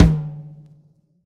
drum-hitclap.ogg